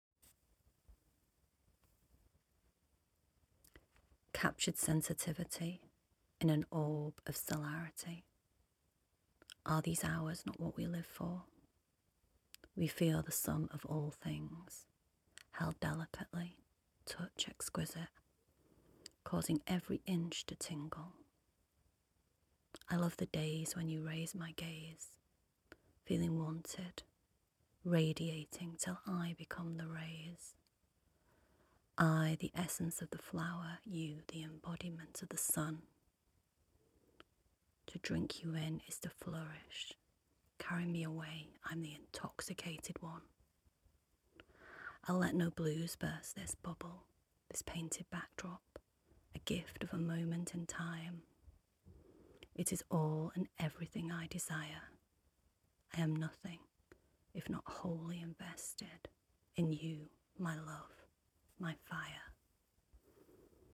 I am mesmerised by the recitation.
Its beyond beautiful how youv read your poem, with so much emotions, can feel the words too!